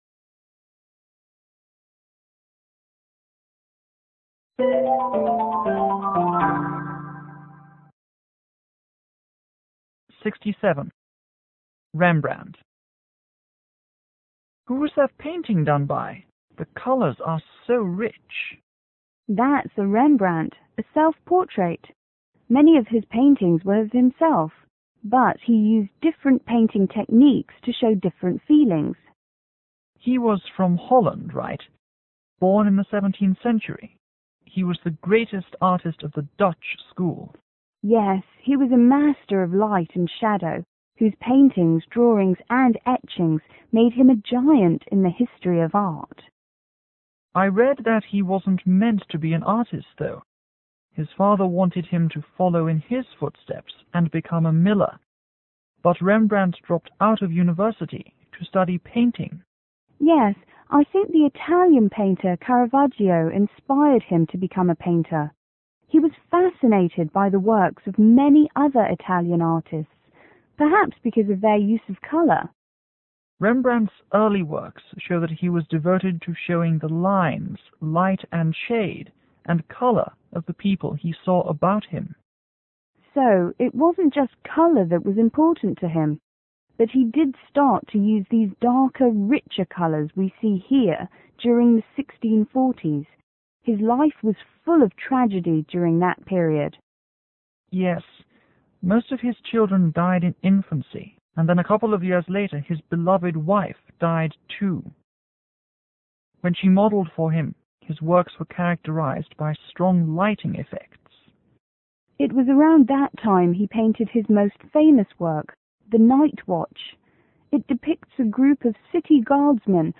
S1 : Student 1       S2 : Student 2